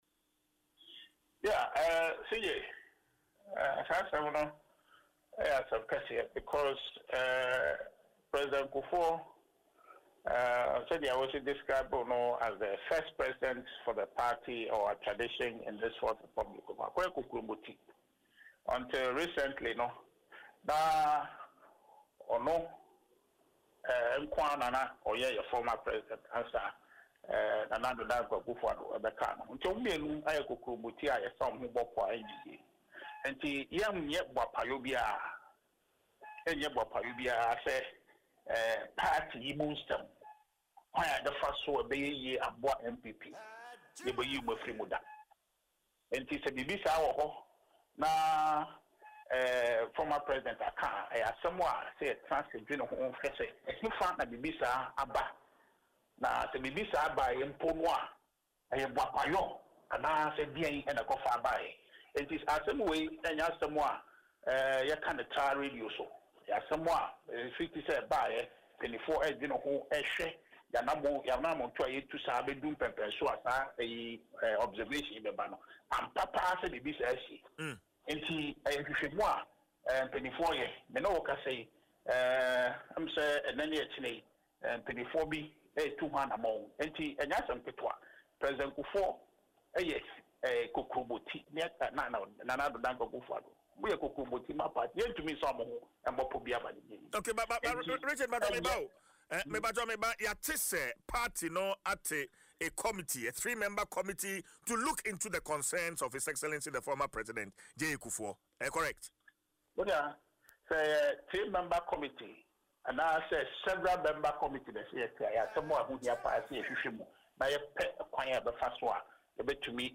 Speaking in an interview on Adom FM’s Dwaso Nsem morning show